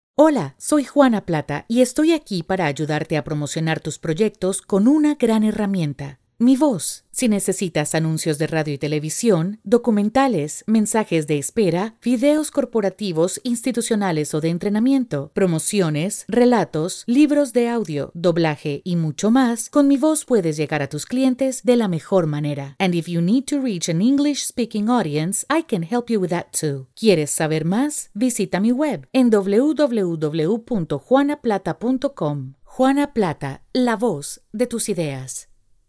Spanish, Female, Voice Over, Bilingual english/Spanish, neutral accent, elegant. colorful, narration, commercials, e-learning, Corporate, training
Sprechprobe: Industrie (Muttersprache):
Spanish/English (light latino accent) with 20+ years in the industry.